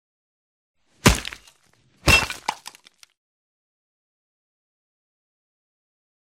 Звуки томагавка
два мощных удара по телу (разрублена часть)